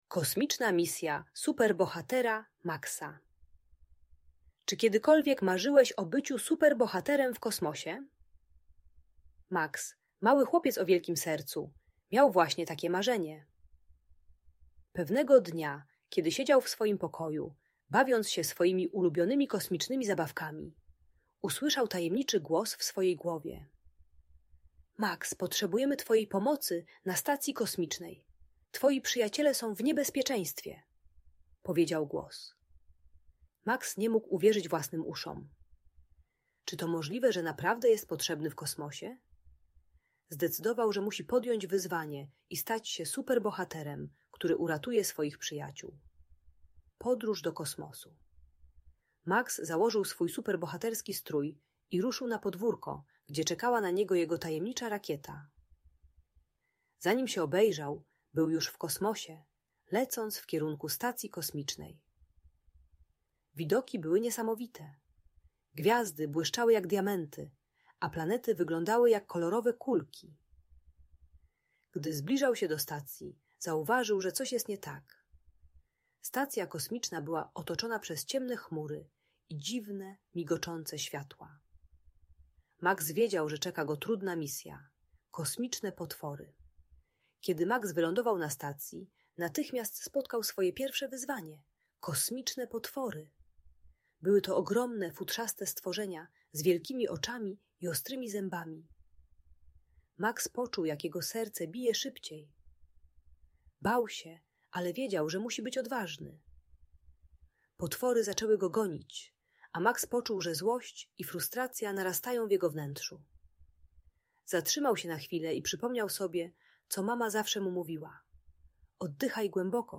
Kosmiczna Misja Superbohatera Maksa - Historia o Odwadze - Audiobajka